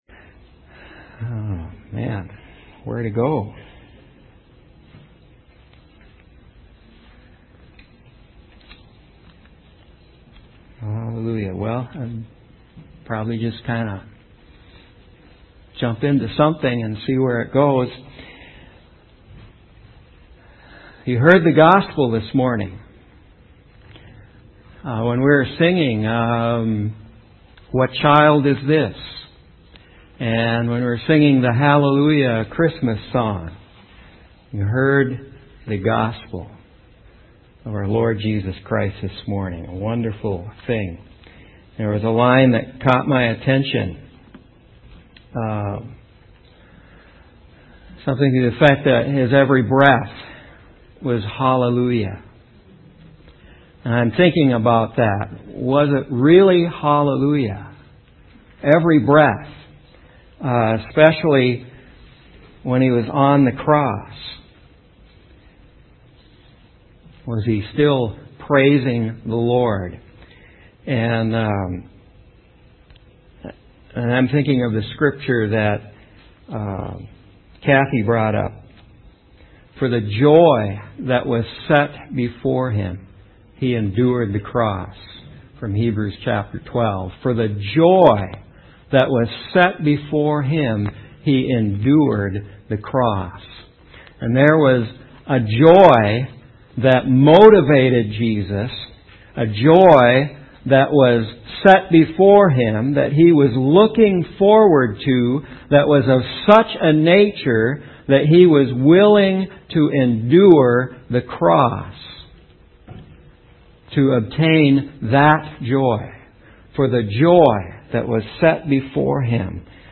| Mt Zion Church, Bemidji